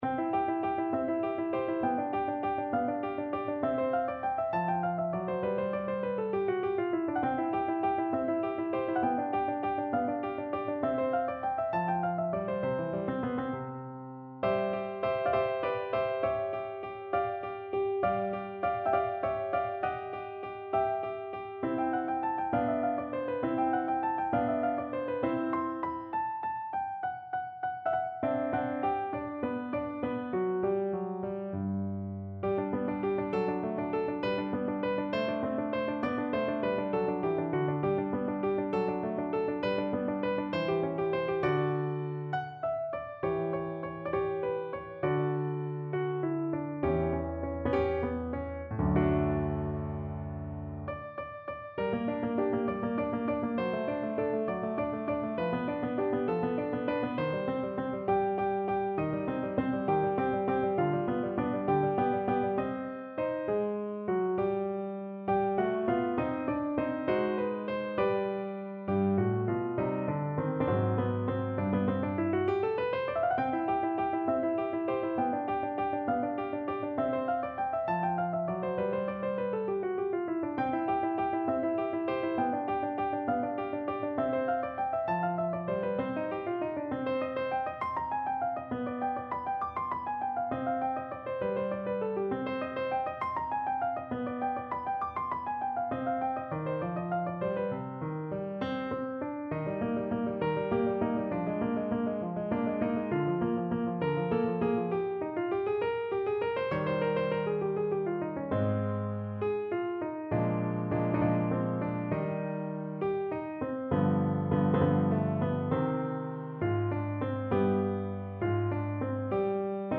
No parts available for this pieces as it is for solo piano.
Easy Level: Recommended for Beginners with some playing experience
Piano  (View more Easy Piano Music)
Classical (View more Classical Piano Music)